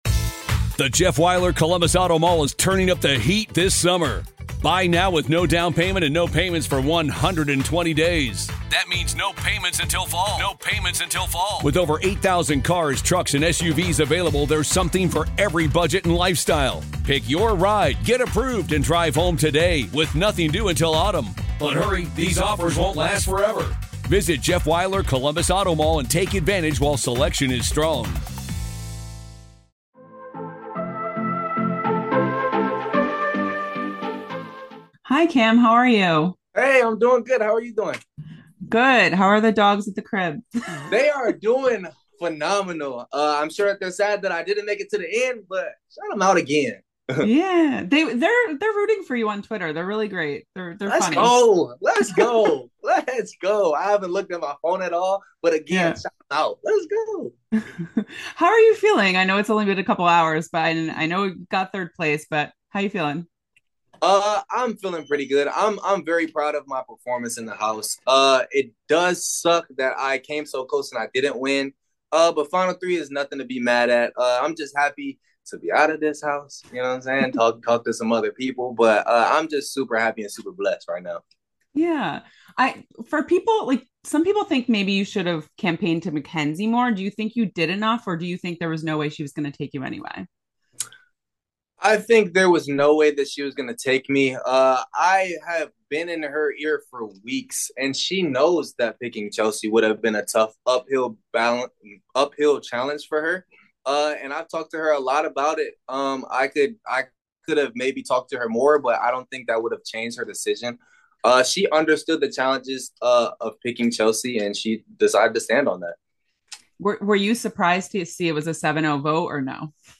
Big Brother 26 Exit Interview